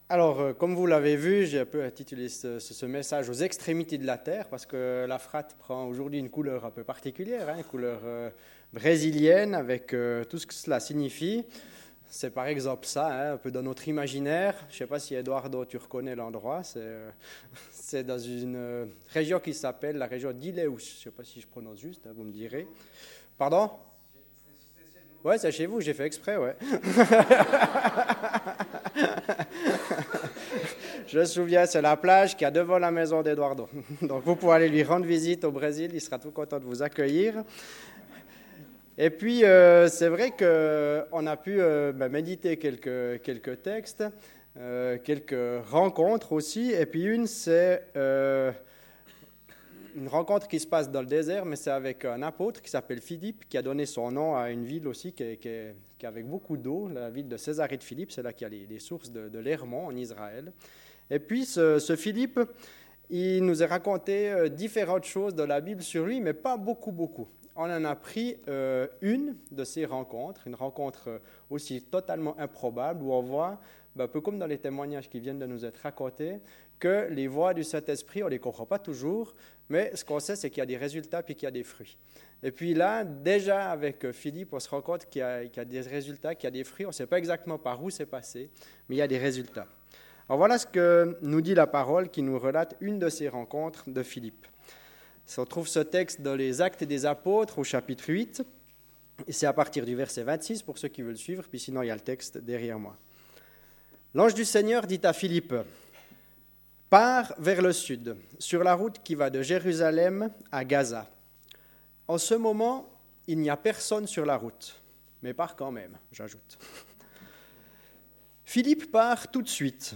Culte de baptêmes du 30 septembre 2018